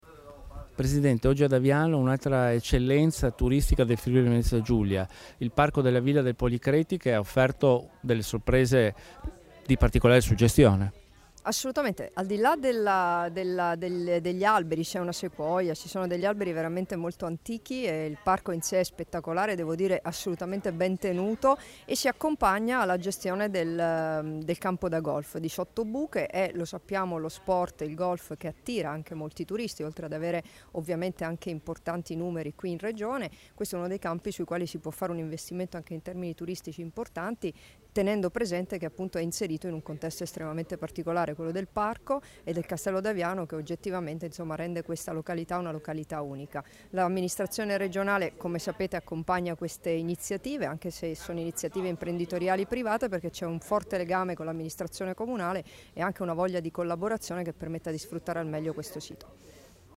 Dichiarazioni di Debora Serracchiani (Formato MP3) [969KB]
a margine della visita al borgo del Castello di Aviano (PN) e all'imponente spazio verde del Parco naturale di Villa Policreti, rilasciate ad Aviano il 9 settembre 2016